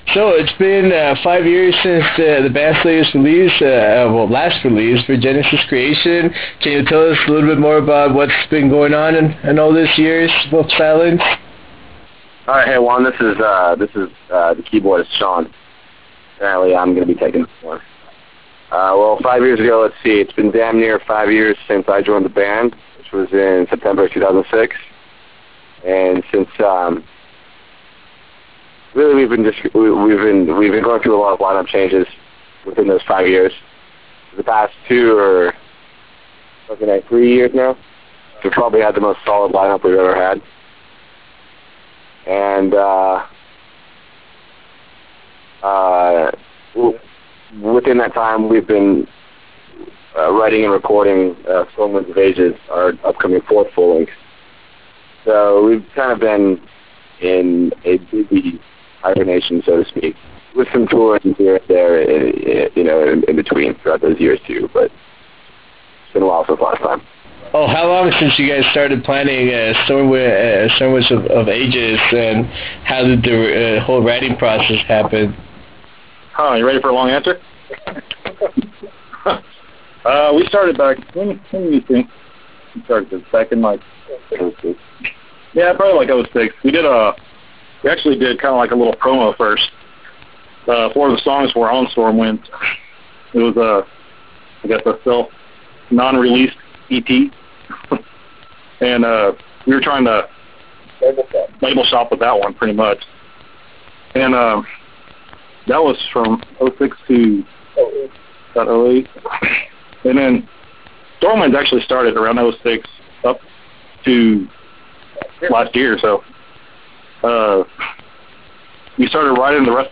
Interview with Vesperian Sorrow
In the eve of the impending release of the band's masterpiece "Stormwinds of Ages", we managed to have a conversation with the band after one of their rehersals to discuss this release. In this interview we talk about the long process behind the recording of this excellent album.